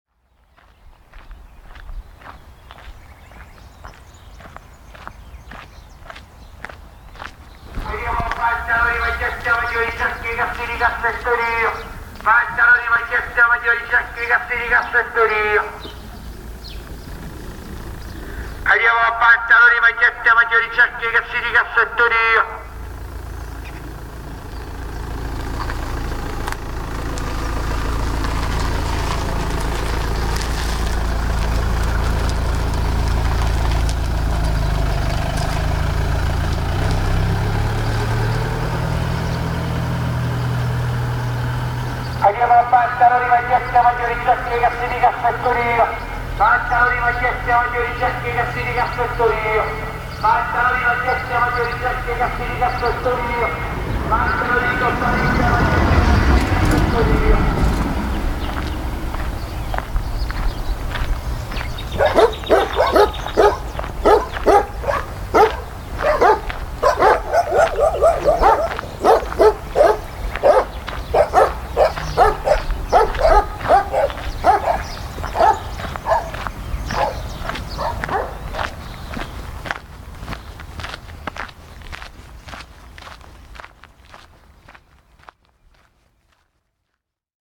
Le vendeur ambulant et les chiens
vendeur_ambulant.mp3